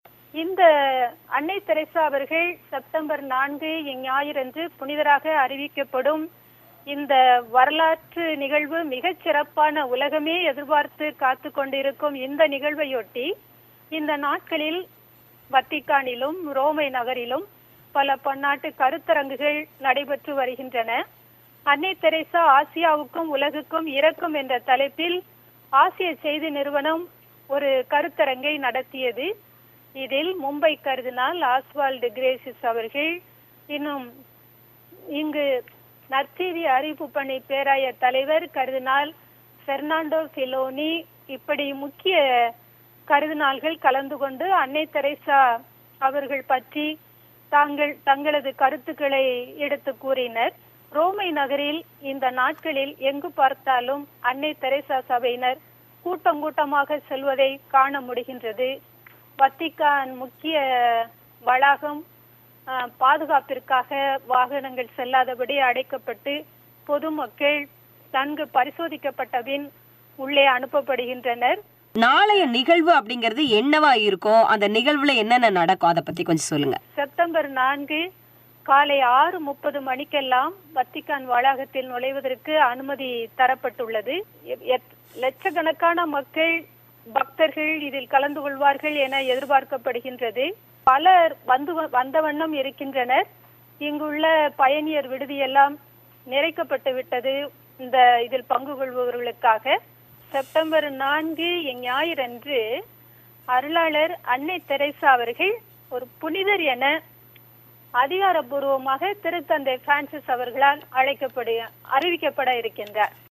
வத்திக்கான் நகரில் அன்னை தெரசாவிற்கு புனிதர் பட்டம் அளிக்கப்படவுள்ளது பற்றிய பேட்டி.